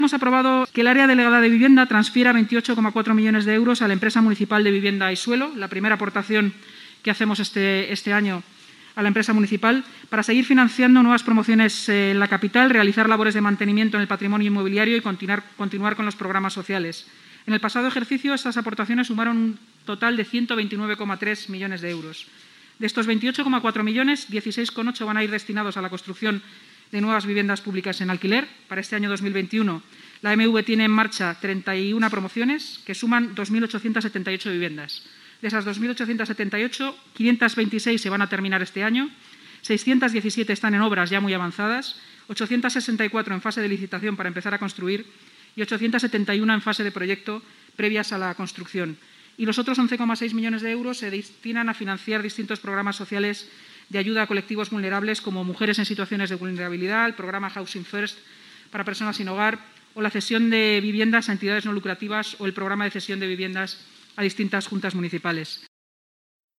Nueva ventana:Declaraciones de la portavoz municipal, Inmaculada Sanz